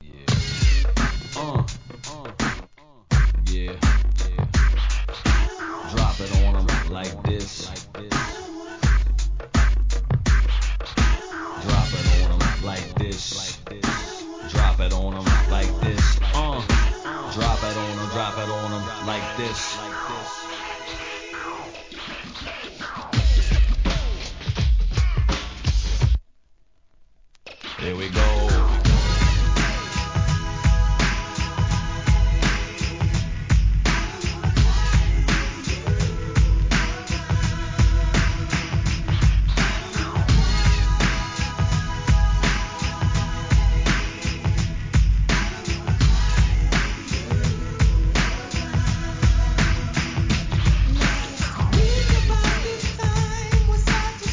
HIP HOP/R&B
1993年のミディアム・ナンバー!!